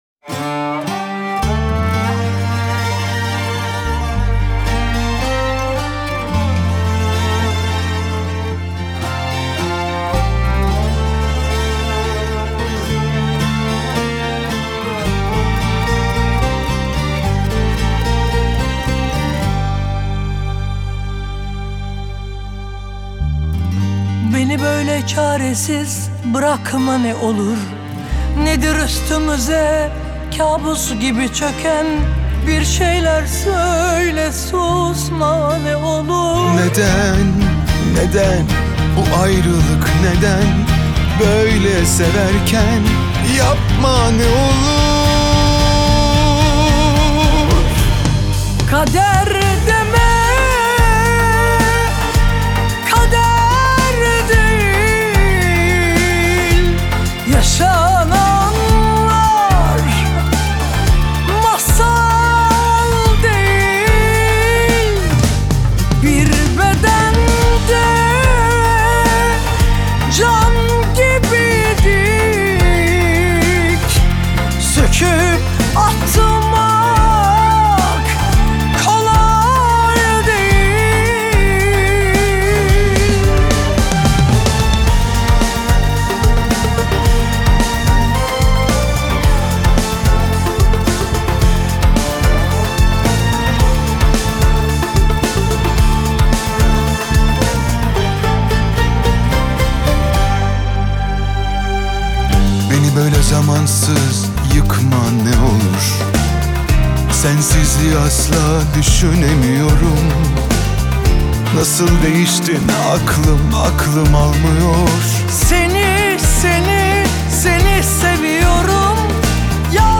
Tür: Türkçe / Pop